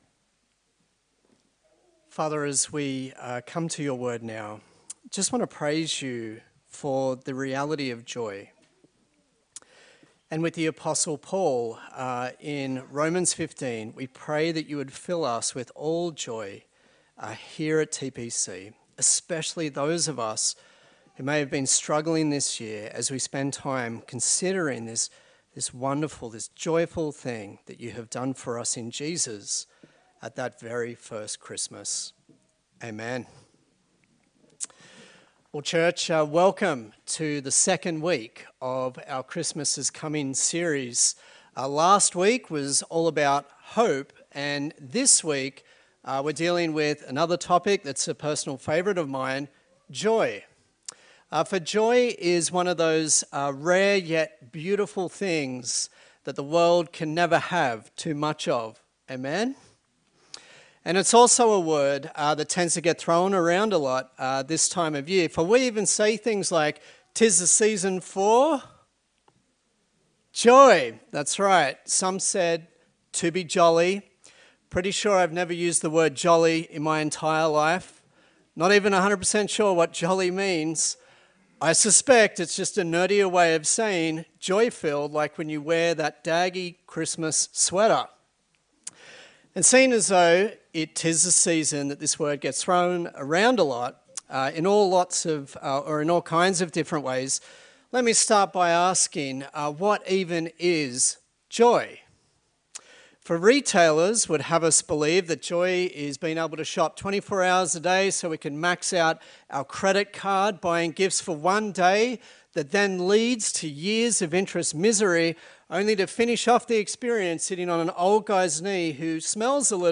11/12/2022 Joy Preacher
Service Type: Sunday Service